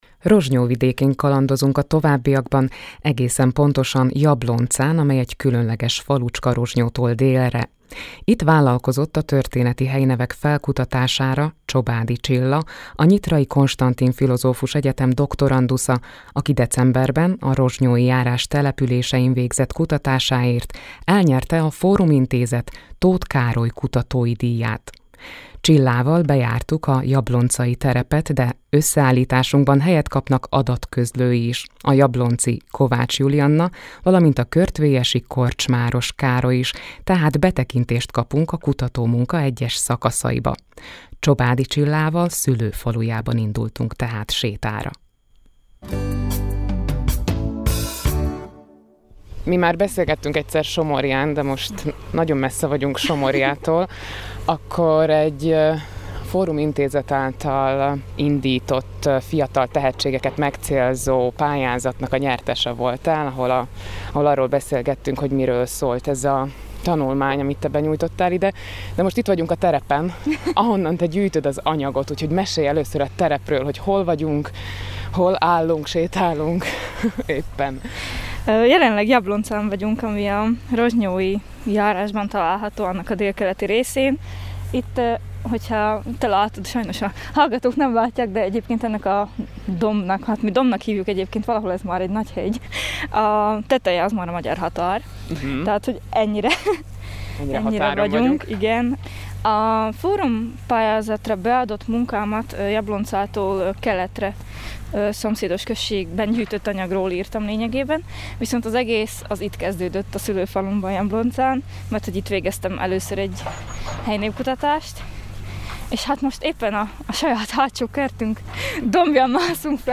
beszélgetése a Pátria Rádióban